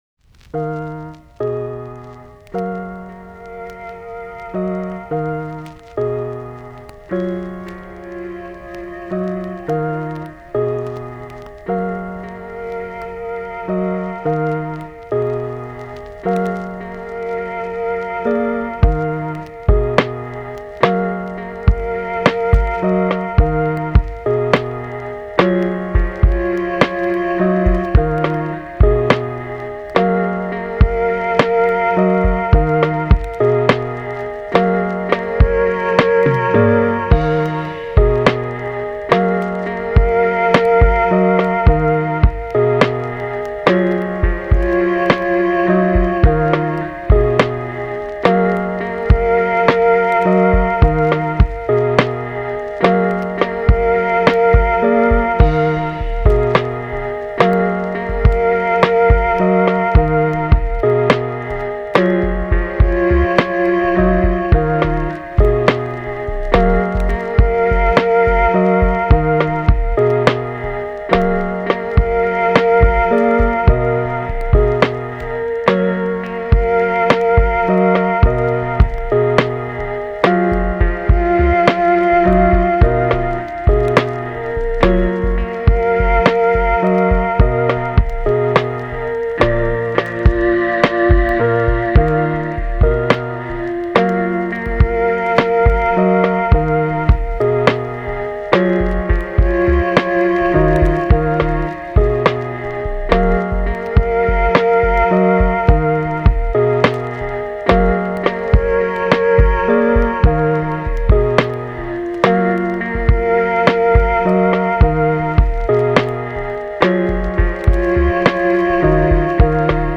チル・穏やか
メロウ・切ない
エンディング , ノスタルジック , ローファイ , 旅立ちのとき